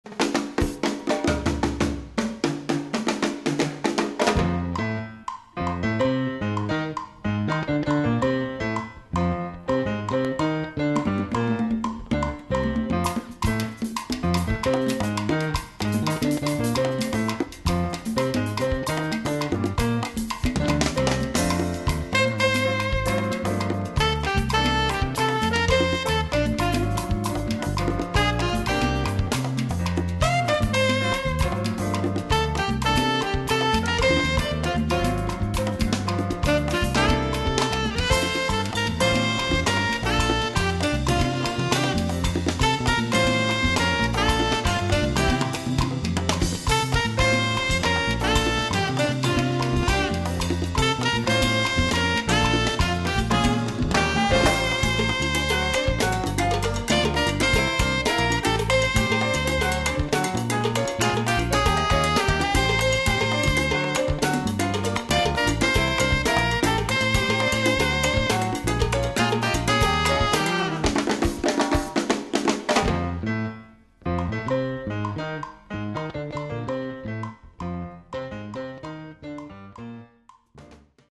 Category: combo
Style: guaguancó
Instrumentation: combo (quintet) tenor sax, rhythm (4)